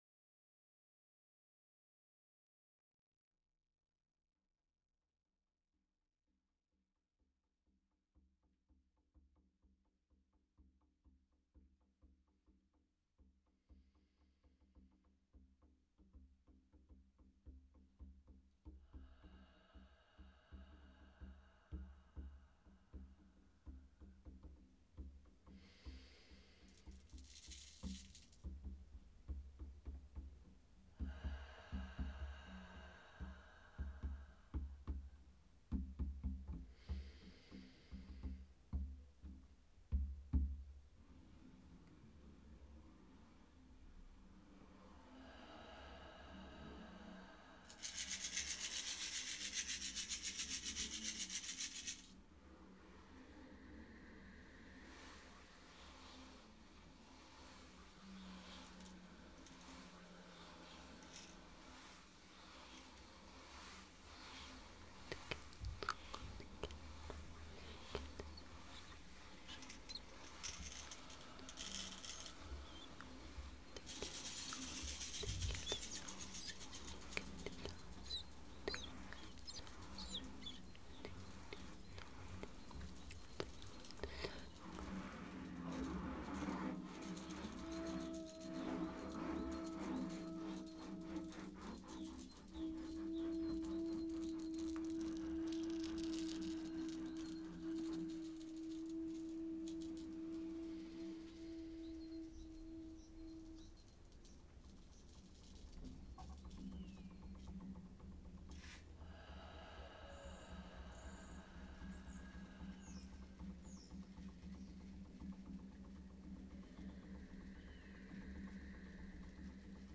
djembé et berimbau Voix, bruitage, mixage